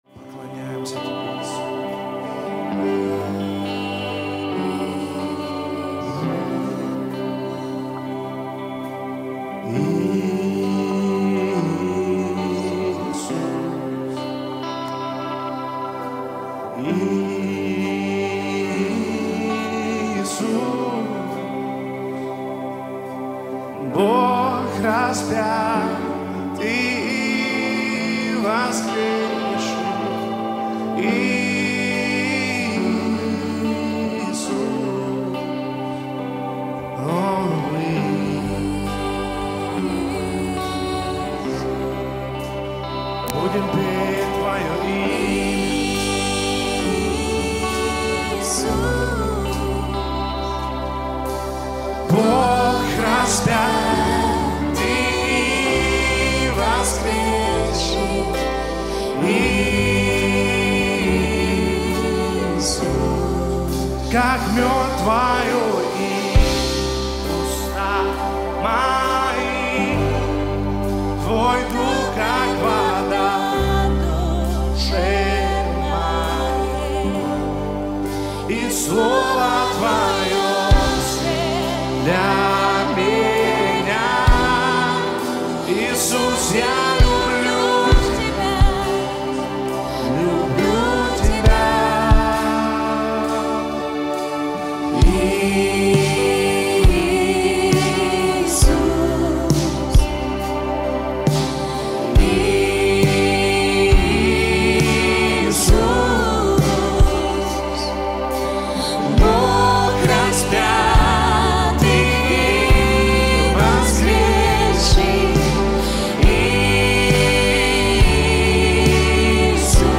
1514 просмотров 647 прослушиваний 42 скачивания BPM: 70